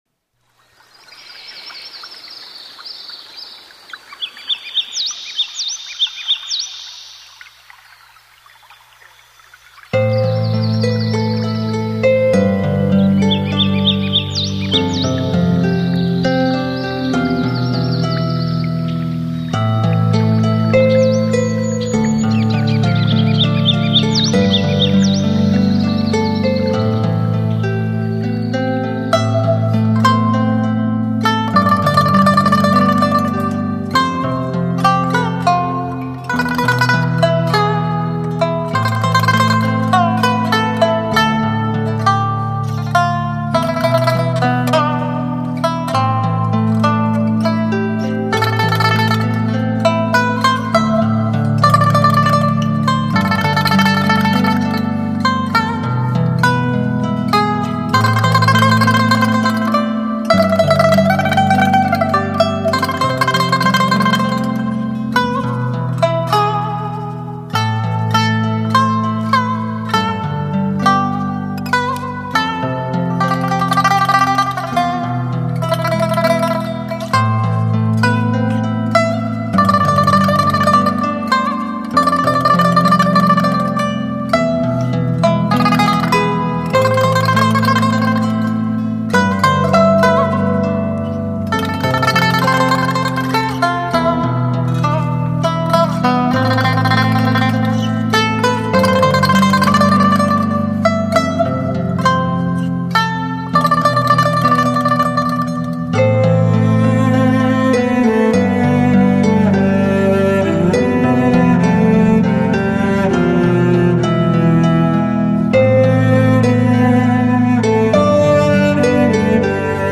[16/7/2012]&写给你的第98封信& 思丝入扣。。。（琵琶） 激动社区，陪你一起慢慢变老！